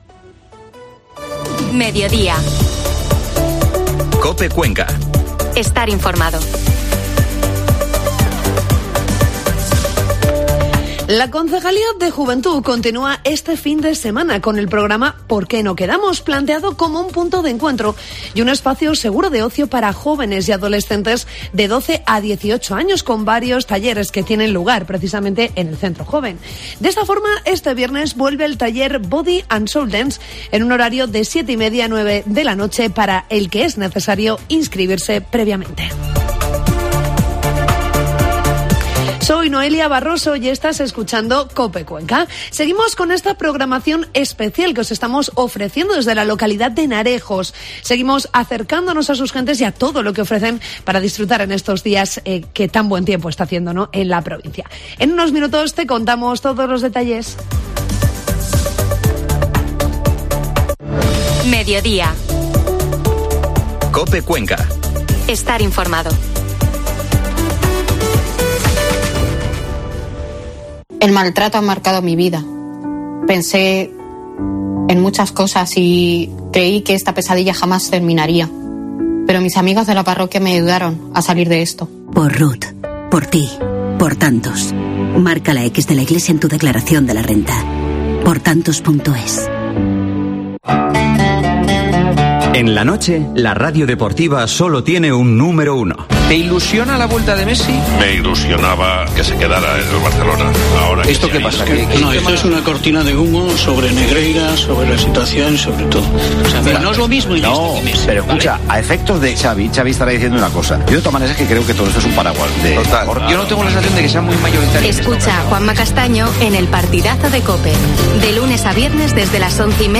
AUDIO: Programa especial de COPE Cuenca desde Heanrejos